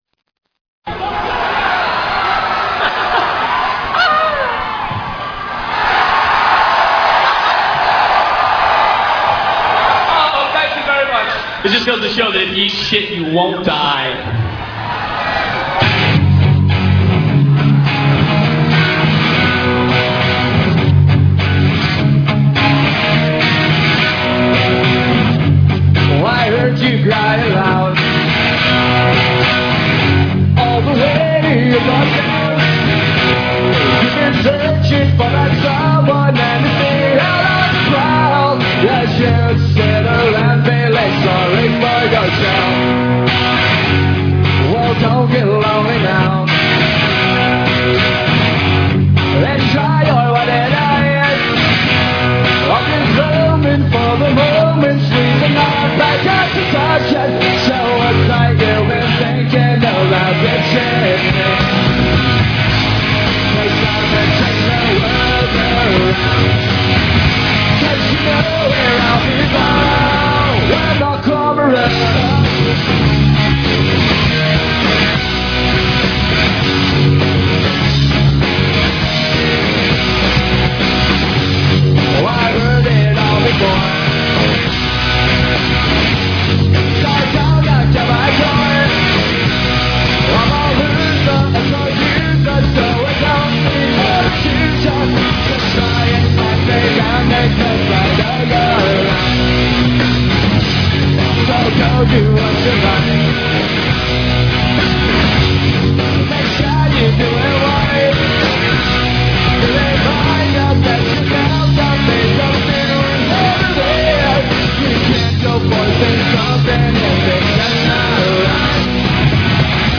Live from Woodstock II, 94